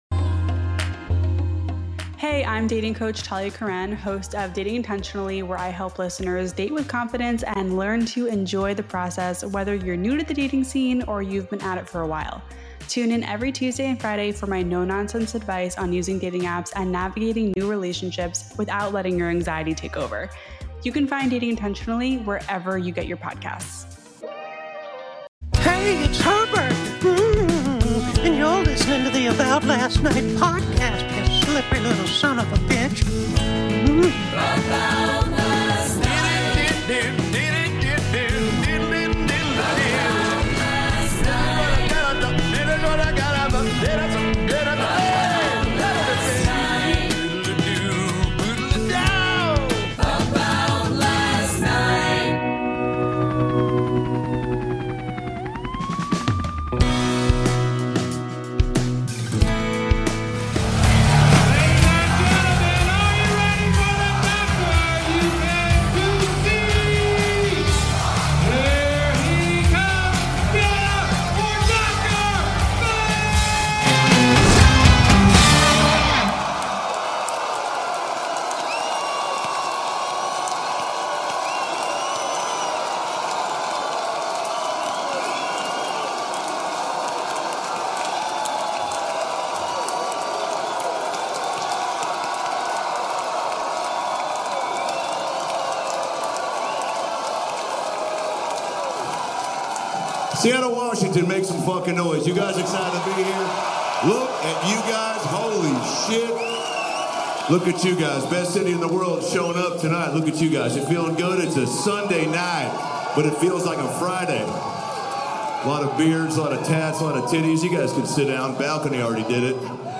Recorded LIVE at the Neptune Theatre on June 30th, 2024!